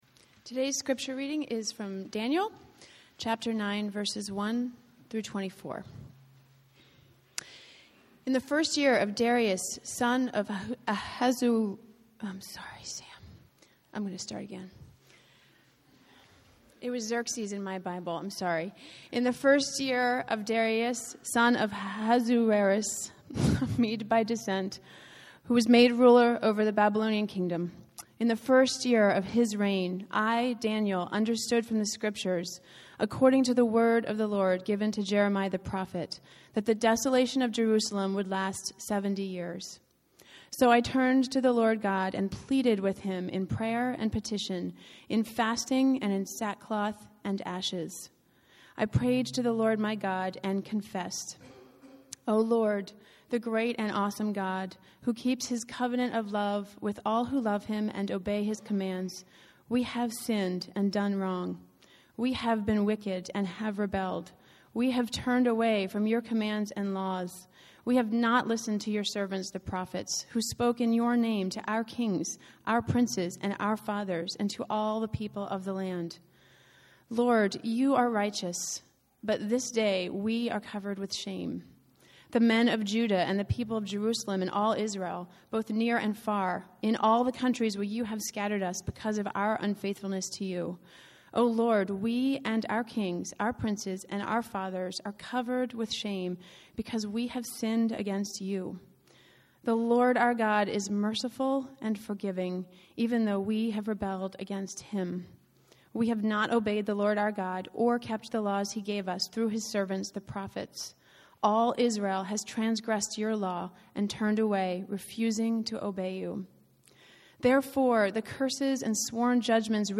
So, periodically, I gave a message in this series like this one (February of 2007) to promote this essential for marriages.